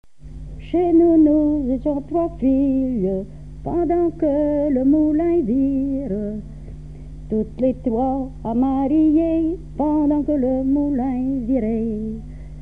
Genre laisse
Pièce musicale inédite